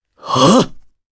Pavel-Vox_Casting1_jp.wav